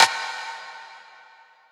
Perc 7.wav